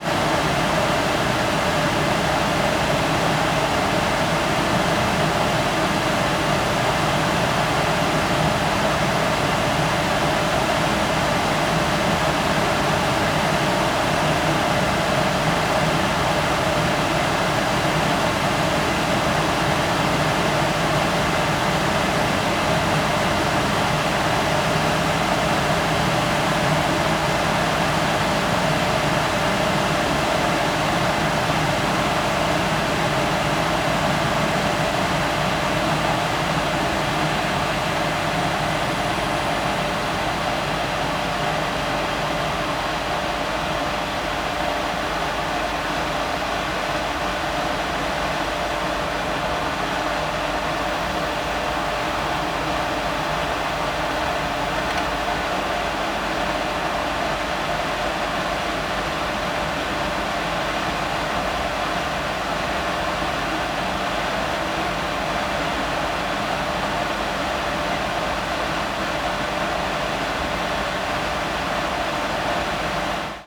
23 Industrial Gas  Boiler room_room tone_boiler near_PAN C.wav